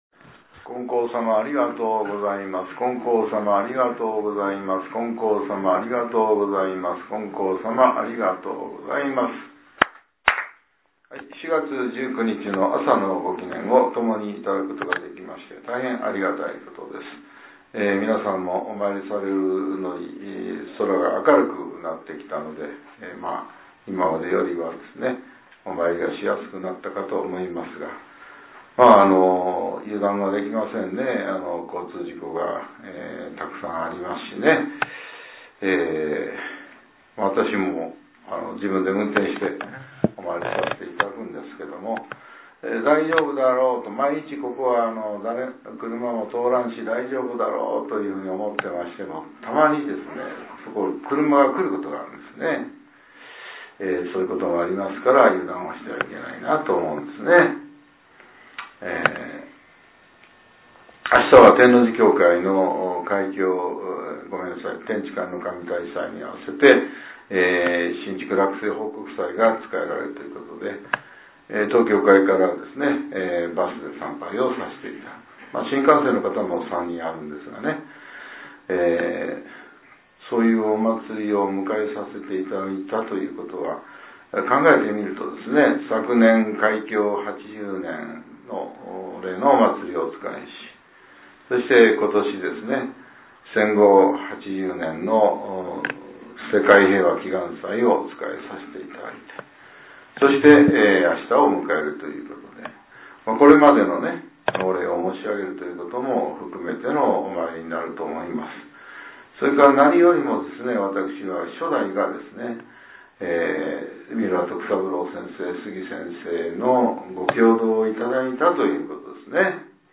令和７年４月１９日（朝）のお話が、音声ブログとして更新されています。